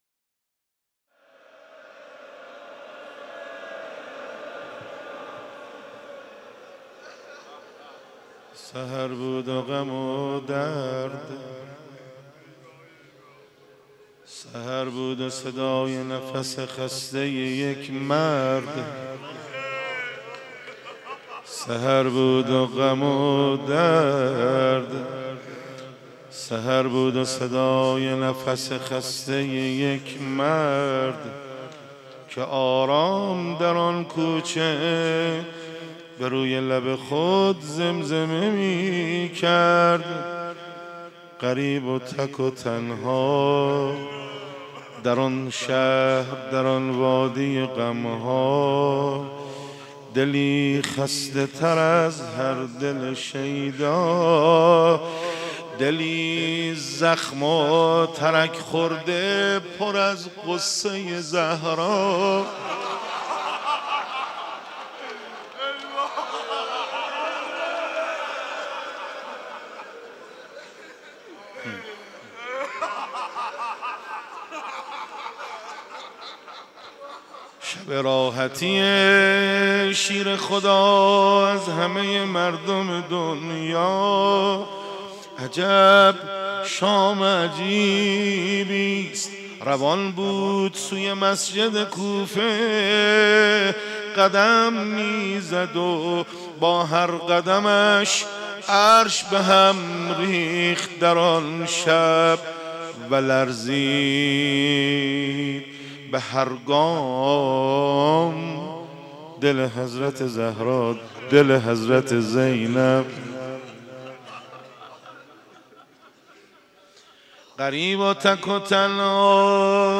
مداحی
در مسجد حضرت امیر(ع) برگزار گردید